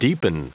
Prononciation du mot deepen en anglais (fichier audio)
Prononciation du mot : deepen